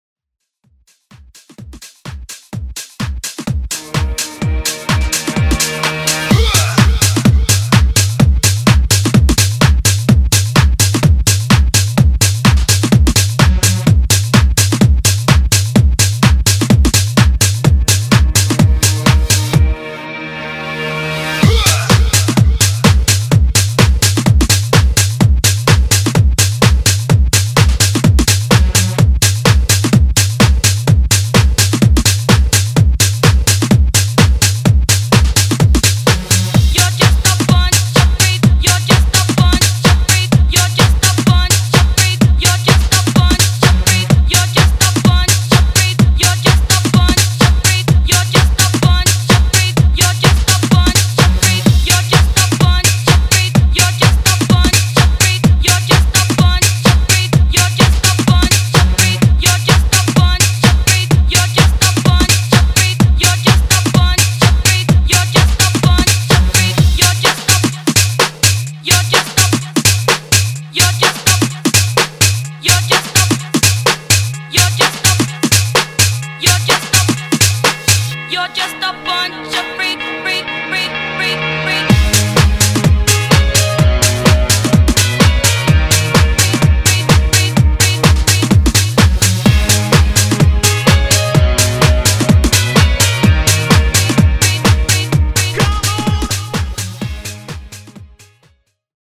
Genre: EDM
Clean BPM: 126 Time